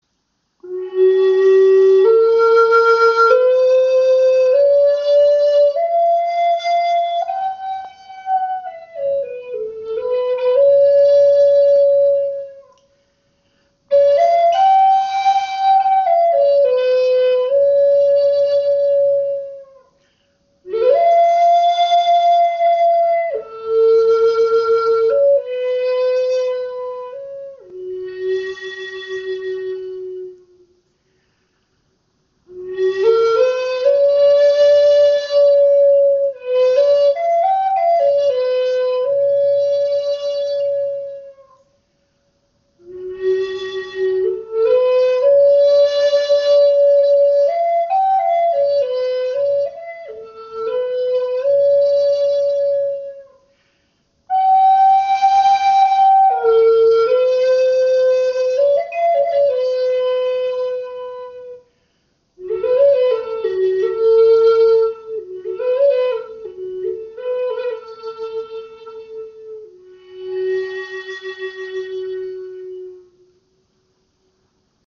Klangbeispiel
Diese Gebetsflöte in G ist auf 432 Hz gestimmt. Sie wurde aus dem Holz eines Nussbaumes geschaffen und abschliessend geölt, so dass sie ein seidenglänzendes Finish hat.
Diese Flöte hat einen angenehmen Luftwiderstand und lässt sich einfach modulieren.